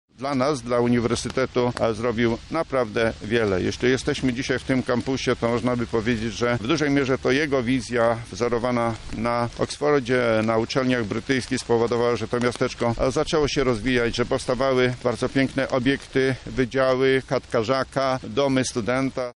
Uroczyste odsłonięcie pomnika byłego rektora UMCS
O jego zasługach dla uniwersytetu mówi profesor Stanisław Michałowski rektor UMCS.